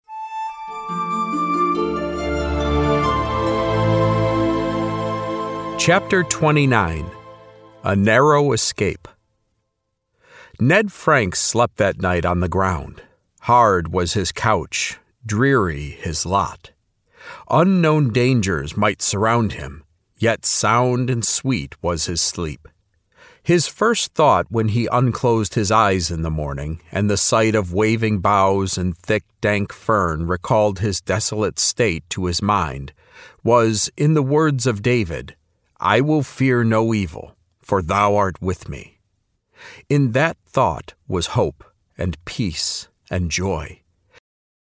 This is an audiobook, not a Lamplighter Theatre drama.
Ned-Franks-Audiobook-Sample.mp3